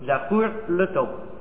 זכור לטוב, which is not repeated by the Chazzan.